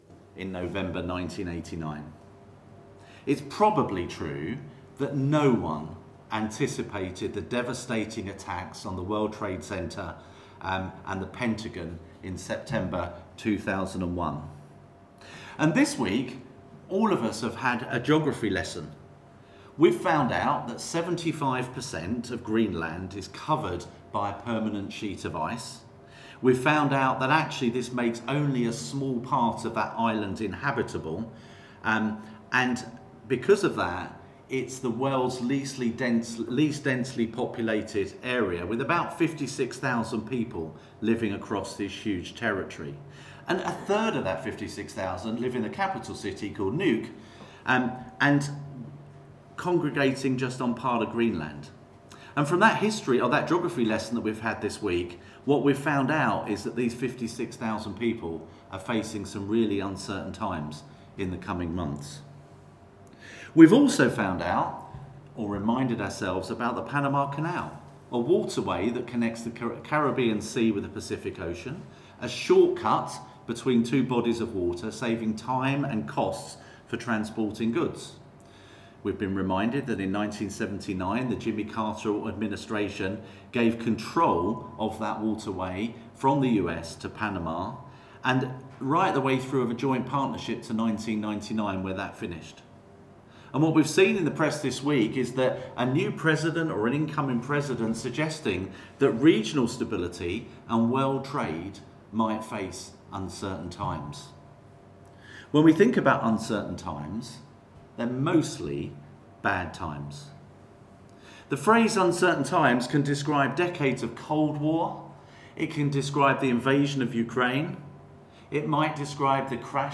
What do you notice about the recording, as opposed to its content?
Media for a.m. Service on Sun 12th Jan 2025 10:30